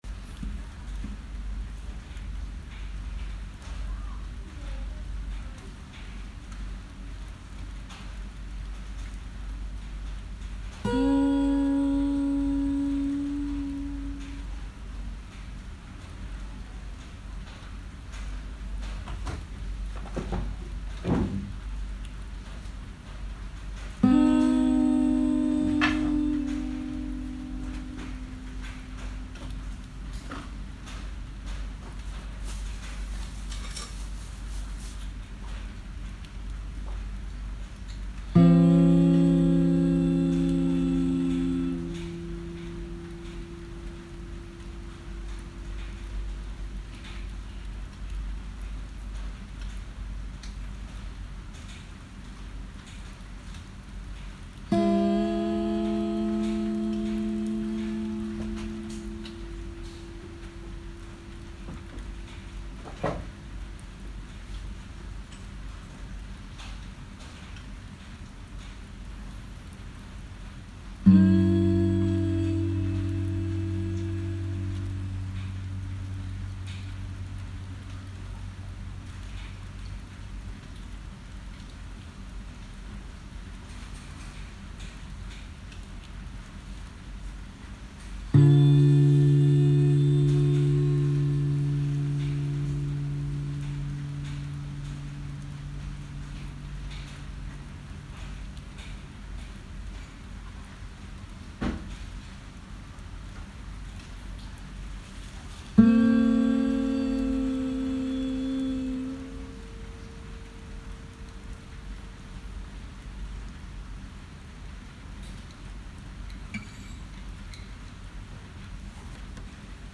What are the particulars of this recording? guitar, vocal on a raining night